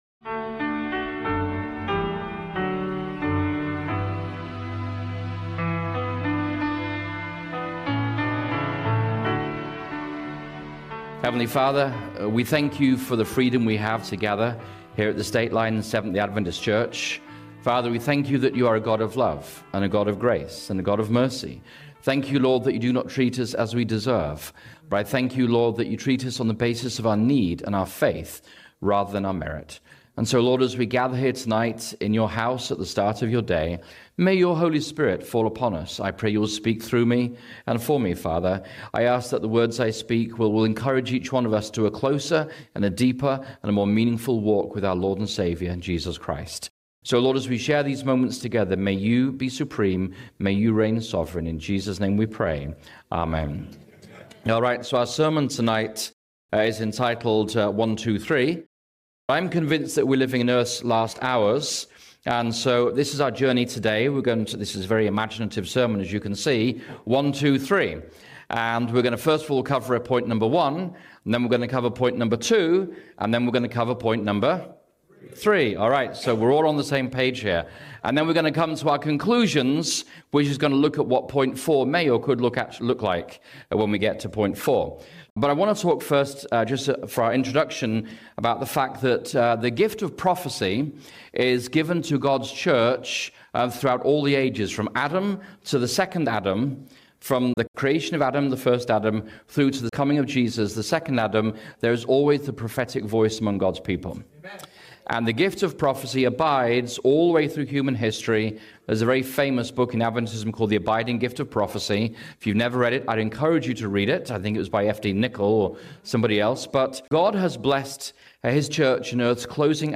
The sermon highlights how history—through World War I, World War II, and the COVID-19 pandemic—reveals repeated failures of church leadership to protect liberty of conscience, often aligning with state power over God’s law. True preparation for the coming Sunday law crisis lies in personal Bible study, daily obedience, and standing firm on the convictions of the Holy Spirit rather than relying on institutional authority.